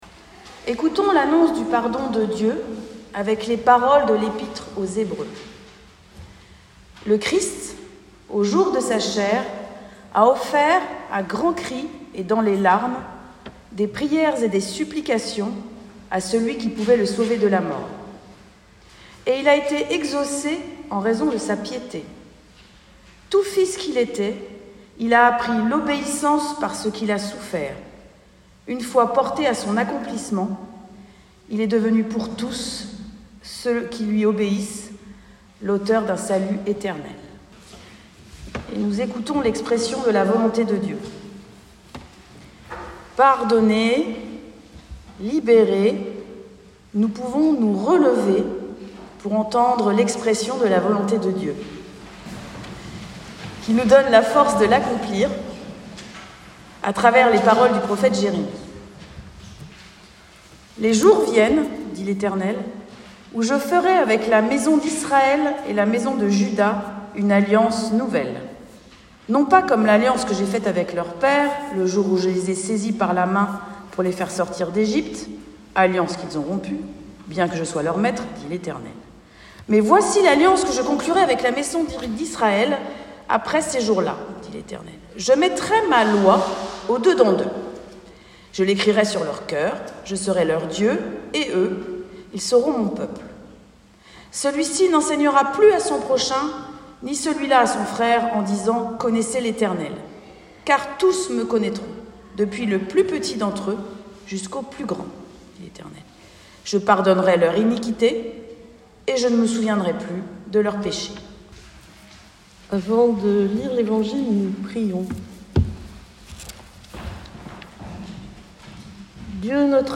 début du culte et prédication 17 mars 2024.mp3 (55.56 Mo)